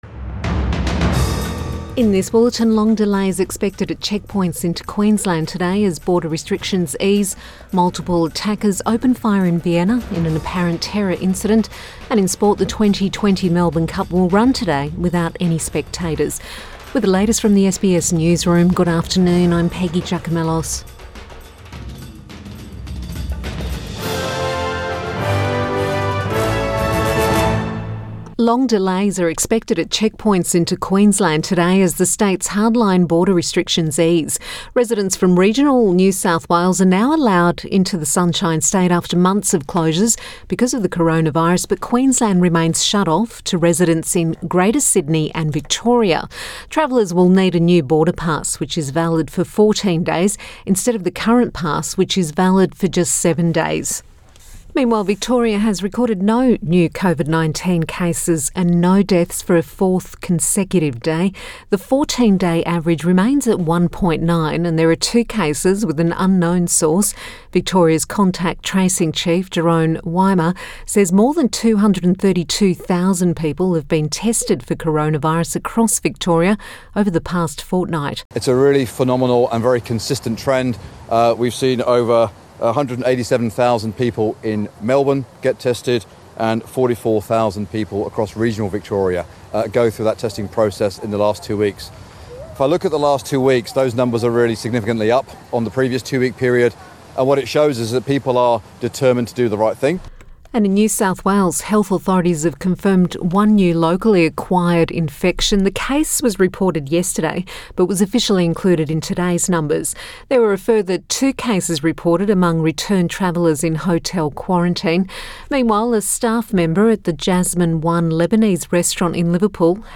Midday bulletin 3 November 2020